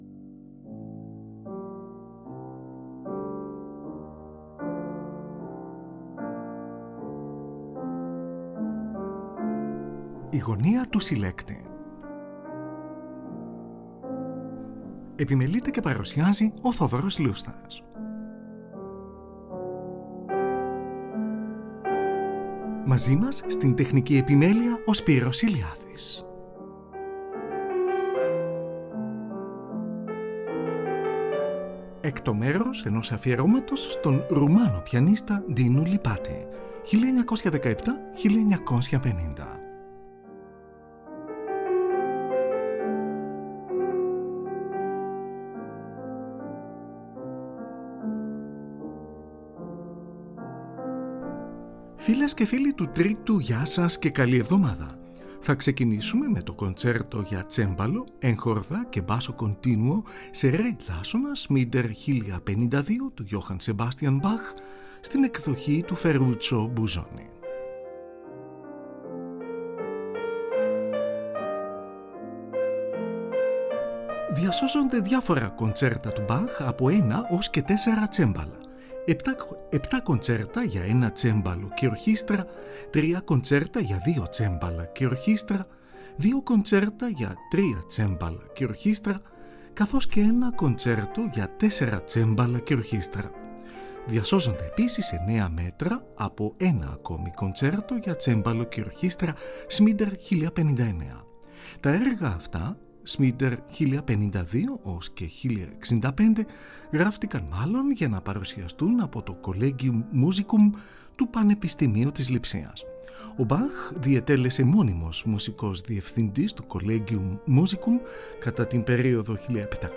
George Enescu: σονάτα για πιάνο αρ.3, έργο 25. Παίζει ο Dinu Lipatti, από ηχογράφηση της Ραδιοφωνίας της Βέρνης, στις 18 Οκτωβρίου 1943.